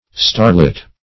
Starlit \Star"lit`\ (-l[i^]t`), a.